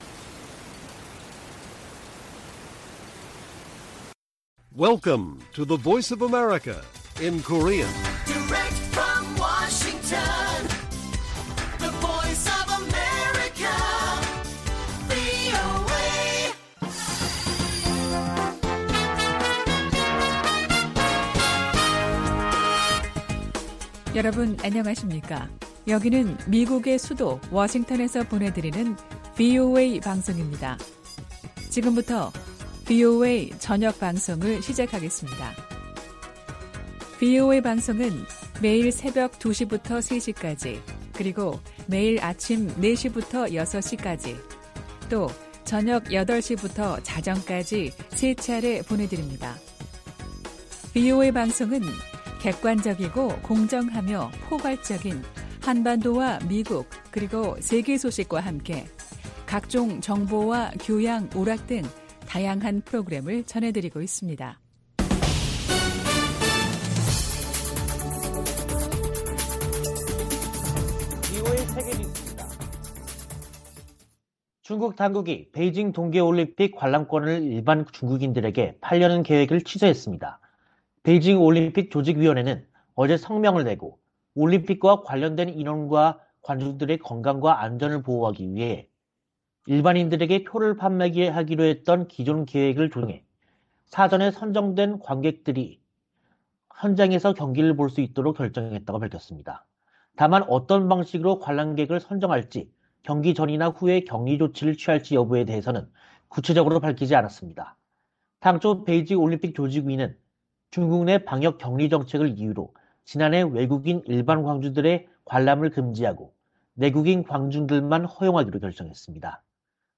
VOA 한국어 간판 뉴스 프로그램 '뉴스 투데이', 2022년 1월 18일 1부 방송입니다. 북한이 17일 쏜 발사체는 '북한판 에이태킴스'인 것으로 파악됐습니다. 성 김 미 국무부 대북특별대표는 북한에 불법 활동을 중단하고 대화에 나서라고 촉구했습니다. 유엔은 북한의 올해 네 번째 미사일 발사에 우려를 표하고, 관련국들이 기존 대화 장치를 활용해 문제 해결에 나설 것을 촉구했습니다.